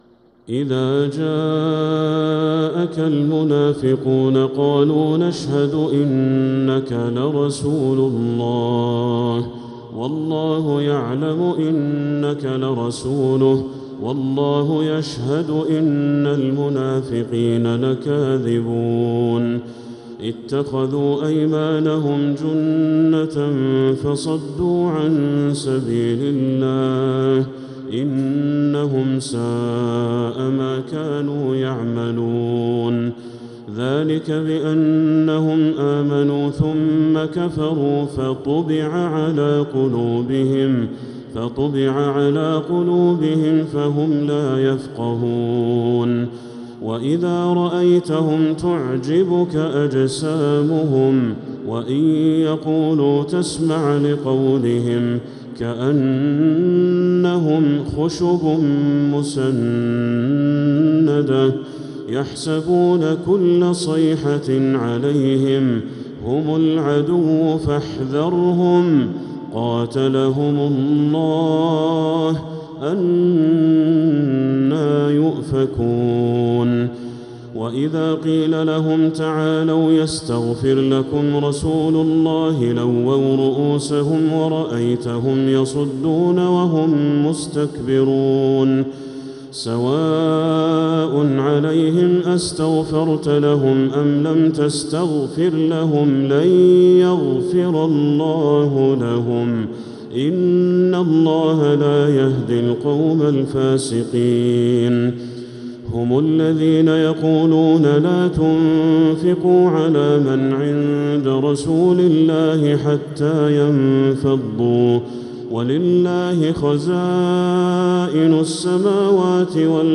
سورة المنافقون كاملة | رمضان 1446هـ > السور المكتملة للشيخ بدر التركي من الحرم المكي 🕋 > السور المكتملة 🕋 > المزيد - تلاوات الحرمين